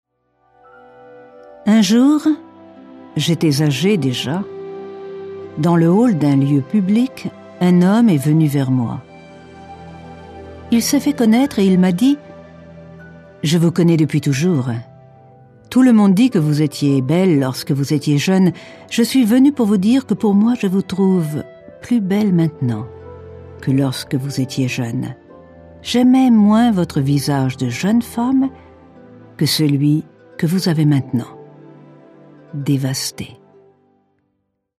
Erfahrene warme französische Stimme.
Kein Dialekt
Sprechprobe: eLearning (Muttersprache):